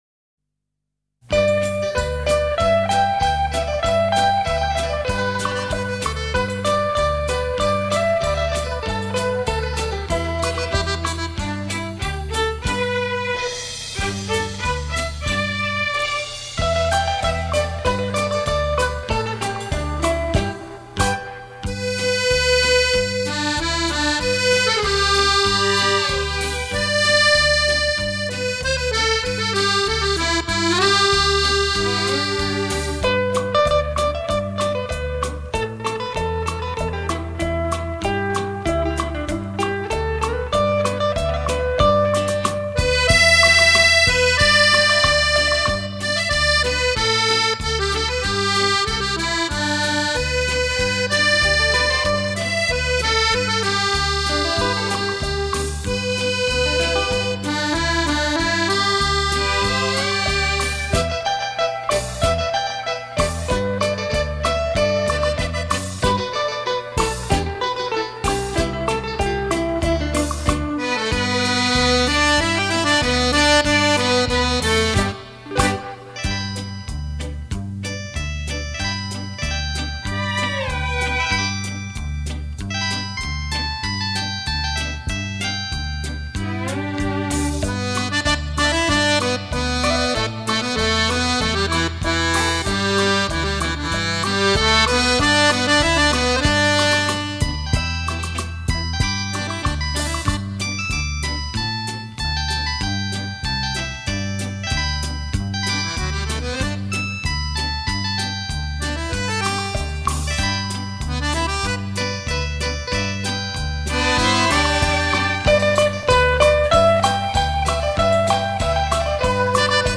怀念的旋律 难忘的记忆